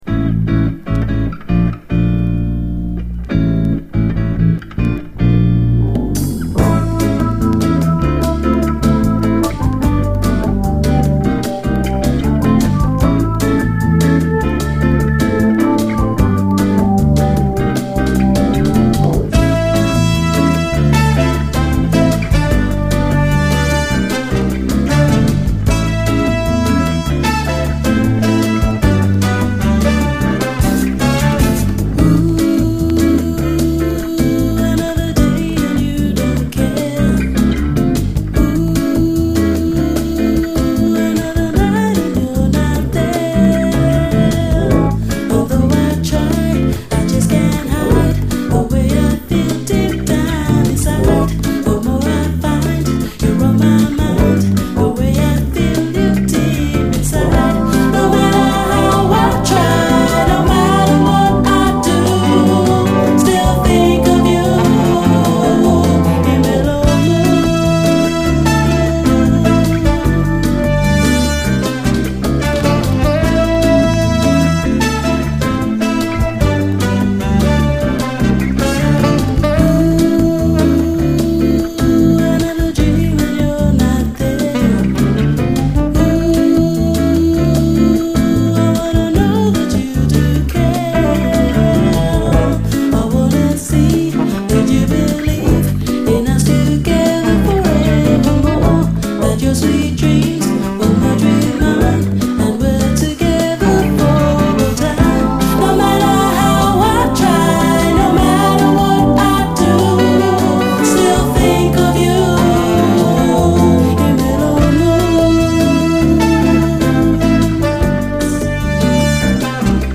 ♪オオオオー・コーラスと王道シカゴ・ソウルのゴージャス・アレンジでフロアをシェイクする、キラー・ドリーミー・ソウル！
巧みなドリーミー・コーラスが舞うグレイト・スウィング！